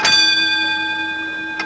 School Bell Sound Effect
Download a high-quality school bell sound effect.
school-bell.mp3